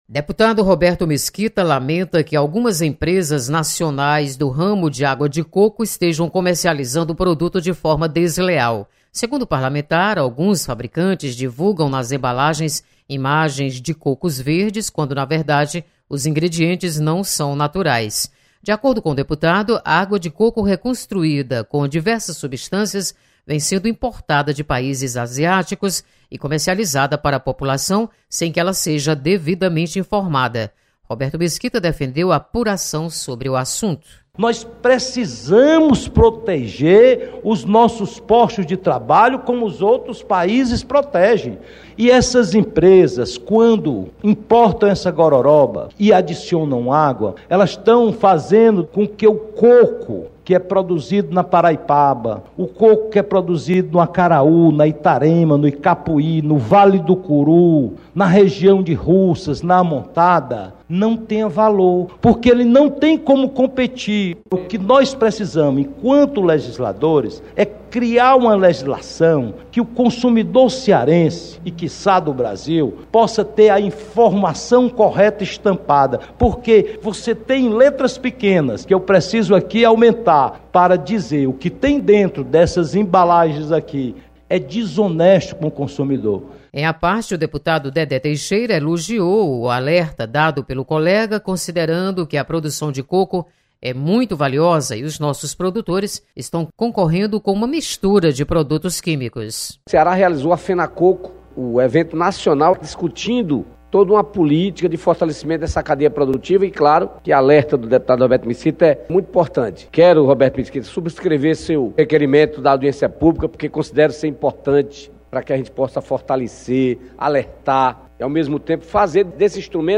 Deputado Roberto Mesquita alerta para prática enganosa de empresas que vendem água de coco. Repórter